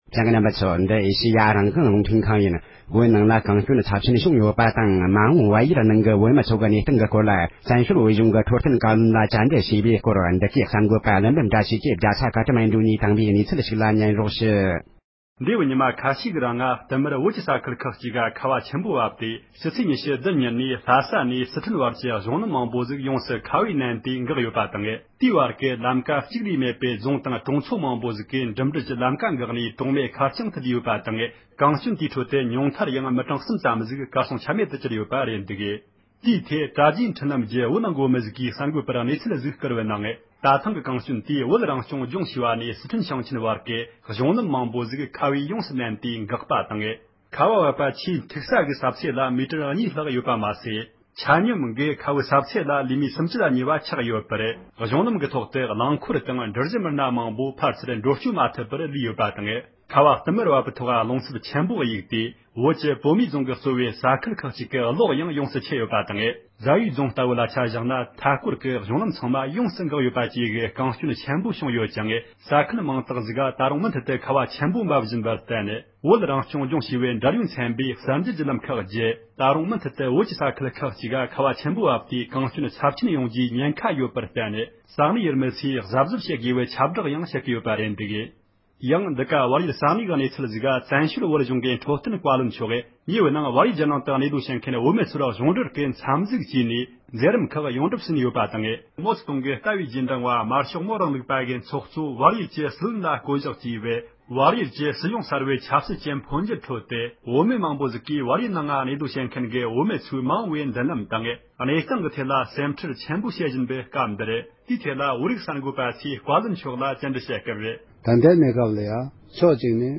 བོད་ནང་ལ་གངས་སྐྱོན་ཚབས་ཆེན་བྱུང་བ་དང་མ་འོངས་བལ་ཡུལ་གྱི་བོད་མི་ཚོའི་གནས་སྟངས་སྐོར་འཕྲོད་བསྟེན་བཀའ་བློན་མཆོག་གིས་གསུངས་བ།
སྒྲ་ལྡན་གསར་འགྱུར།